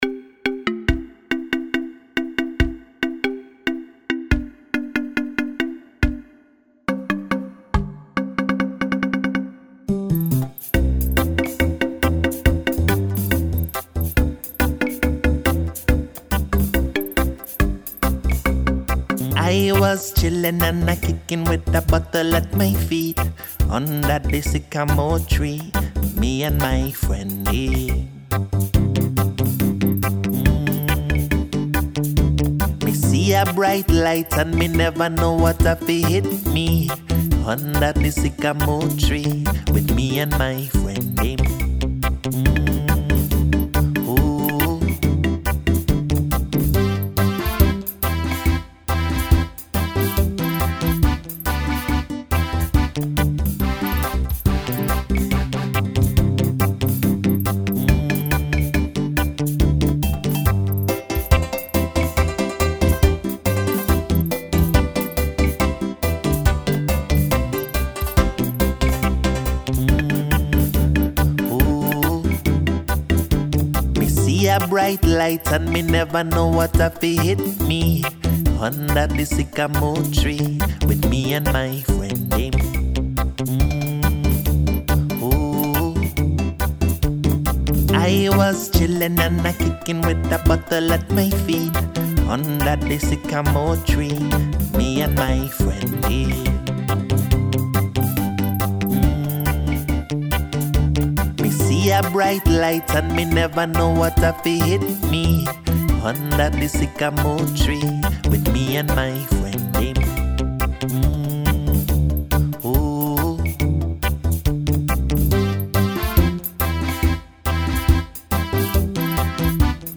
Vocal calypso song from Trinidad/Tobago.
Calypso (Trinidad/Tobago)